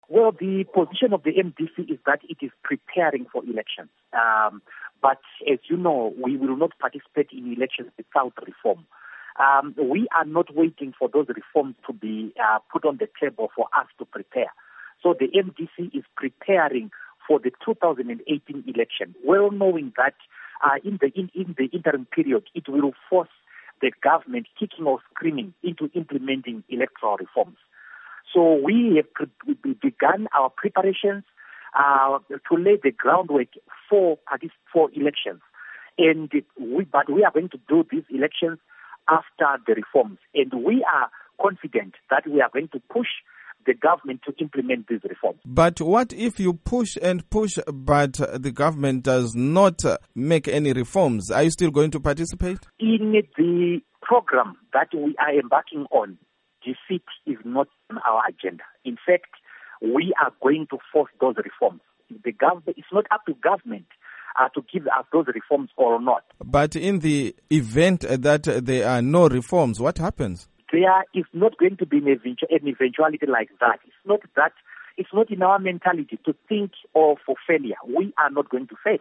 Interview With Douglas Mwonzora